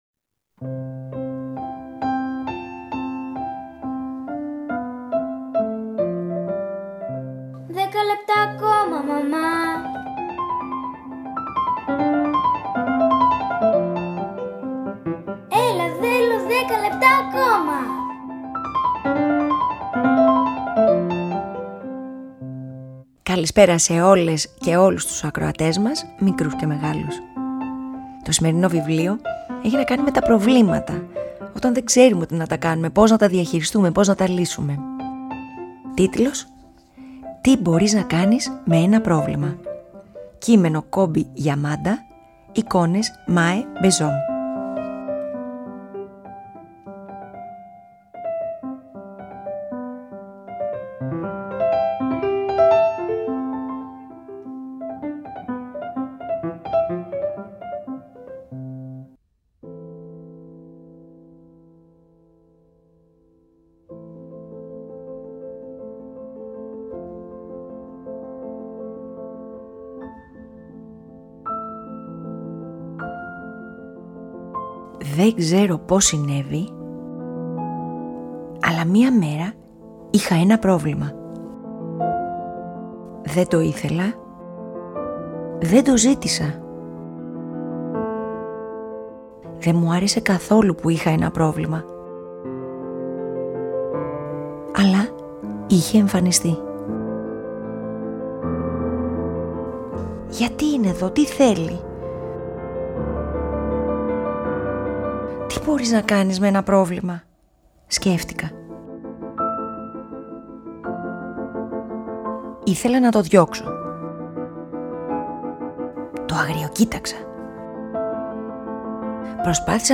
Αφήγηση-Μουσικές επιλογές